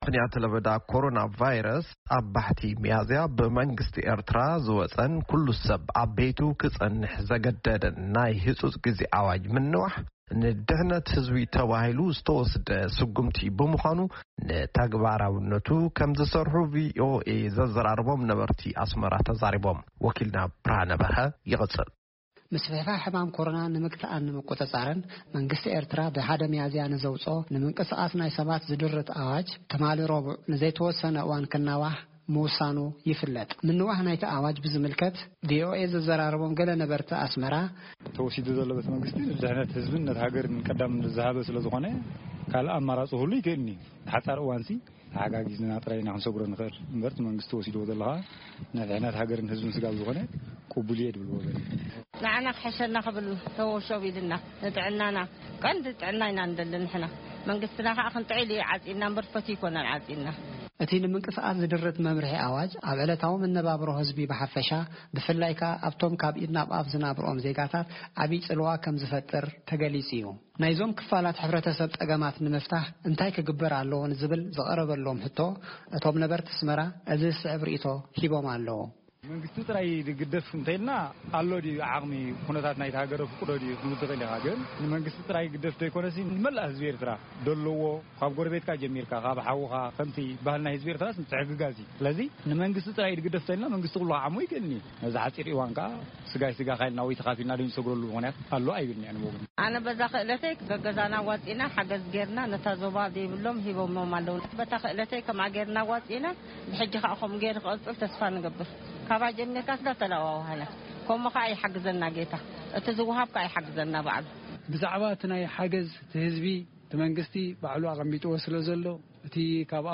ብምኽንያት ለበዳ ኮሮና ቫይረስ 1 ሚያዝያ ዝወጸኩሉ ሰብ ኣብ ቤቱ ክጸንሕ ዘገድድናይ ህጹጽ ግዜ ኣዋጅ ምንዋሕ ንድሕነት ህዝቢ ተባሂሉ ዝተወስደ ስጉምቲ ብምዃኑ ንተግባራውነቱ ክምዝሰርሑ ድምጺ ኣሜሪካ ዘዘራረቦም ነበርት ኣስመራ ተዛሪቦም ።ብ”ምኽንያት ምንዋሕ ናይ ኣዋጅ ንዝህሰዩ ካብ ኢድ ናብ ኣፍ ዝናብርኦም ዜጋታት ምሕጋዝ ናይ ኩሉ ሓላፍናት እዩክብሉ እቶም ወሃብቲ ርእይቶ ተዛሪቦም